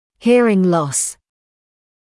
[‘hɪərɪŋ lɔs][‘хиэрин лос]потеря слуха